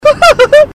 Laugh 6